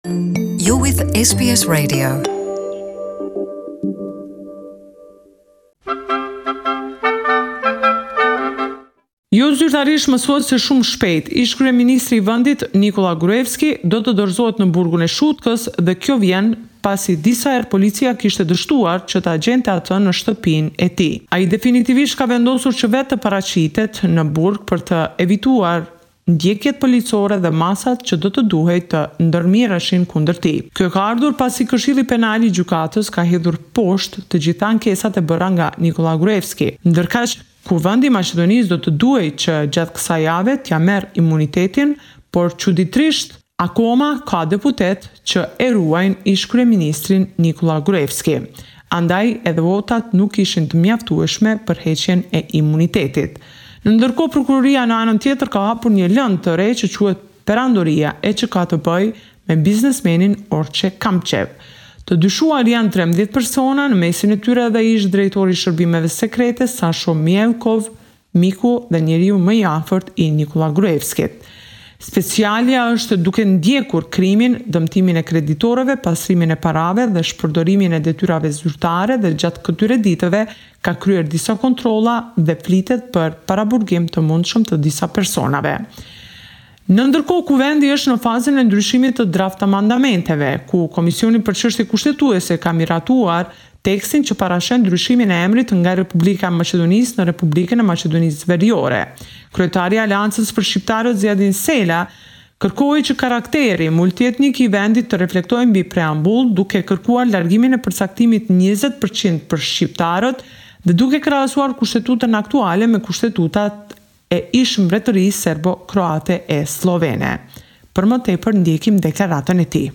This is a report summarising the latest developments in news and current affairs in Macedonia